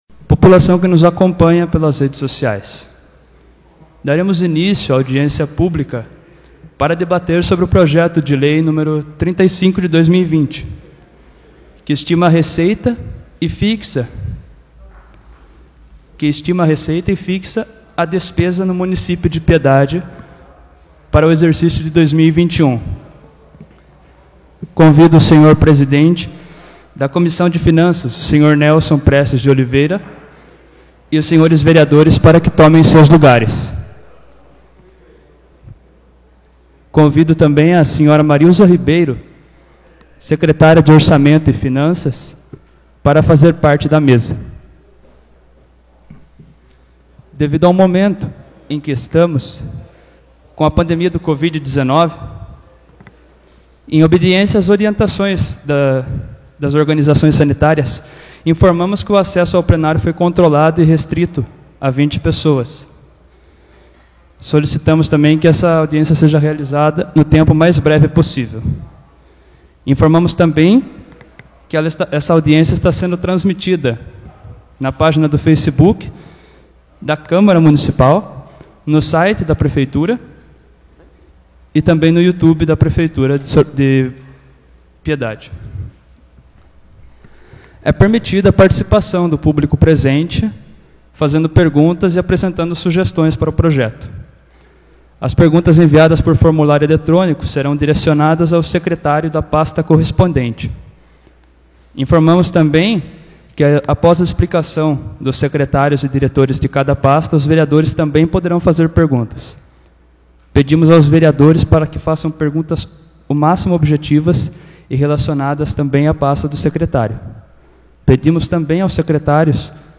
Audiência Pública da LOA - Exercício de 2021